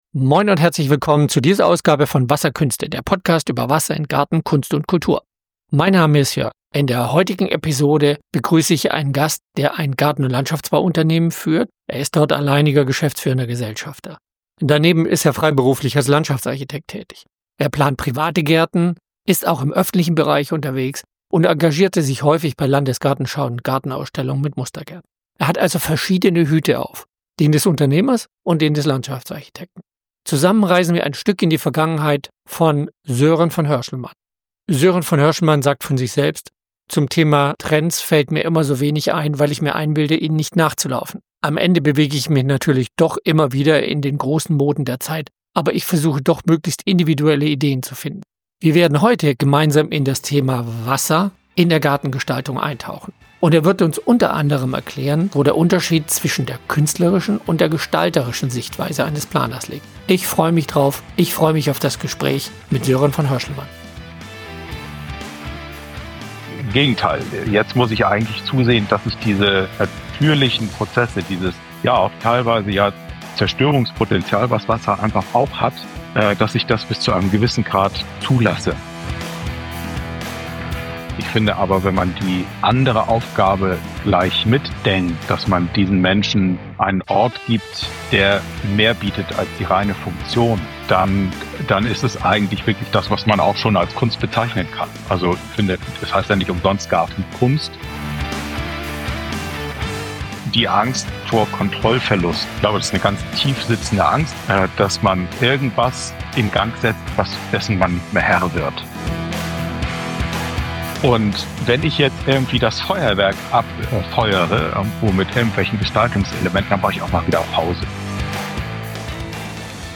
Wir erörtern den wesentlichen Dreiklang Stein - Wasser - Pflanzen und wie diese Elemente harmonisch ineinandergreifen. Im Gespräch werfen wir einen Blick auf Themen wie die ökologische Verschränkung, die Herausforderungen der Gartenplanung in Zeiten des Klimawandels und die essenzielle Rolle von Wasser in all seinen Aggregatzuständen für die moderne Gartengestaltung.